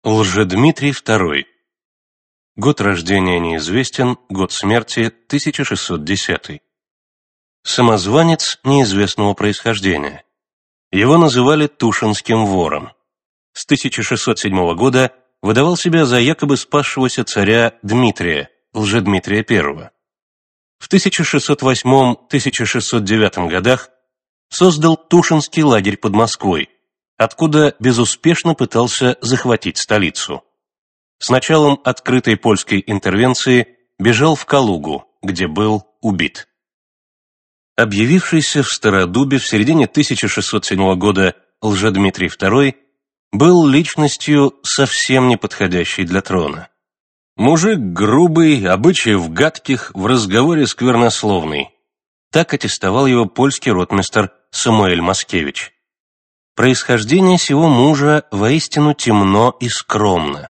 Аудиокнига Авантюристы | Библиотека аудиокниг
Aудиокнига Авантюристы Автор Сборник Читает аудиокнигу Всеволод Кузнецов.